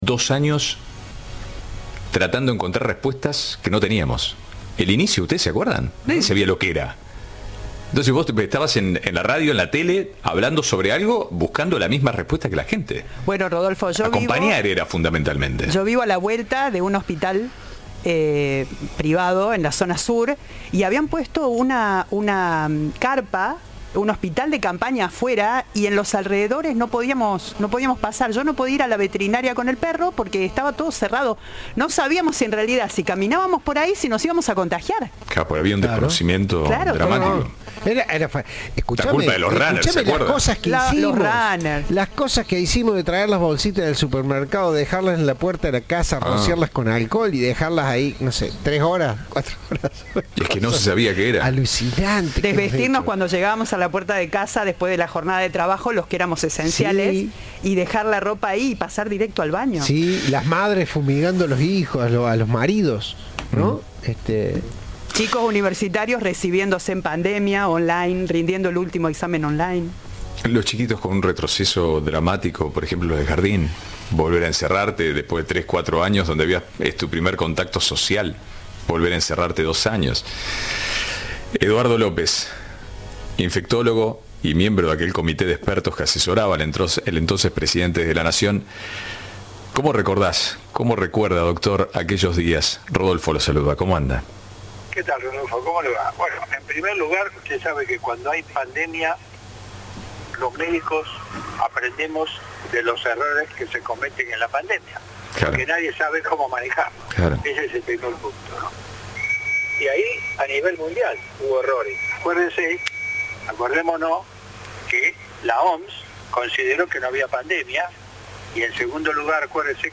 Entrevista de “Ahora País”.